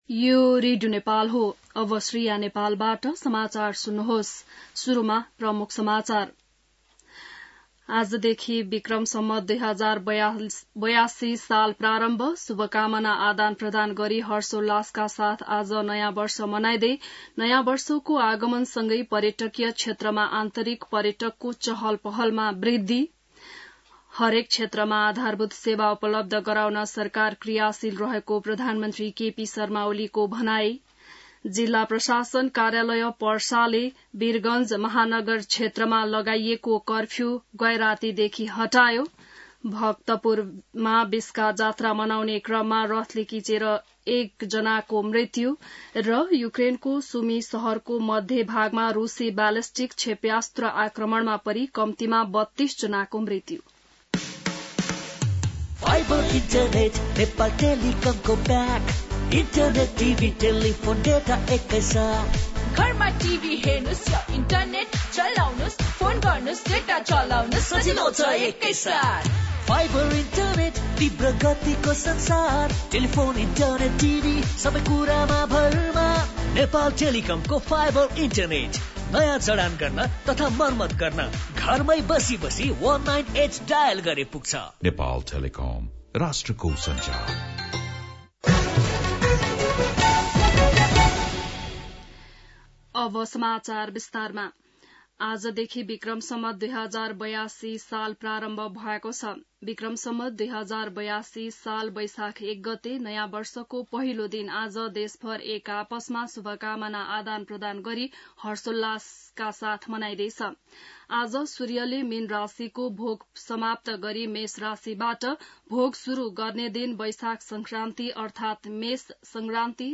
बिहान ७ बजेको नेपाली समाचार : १ वैशाख , २०८२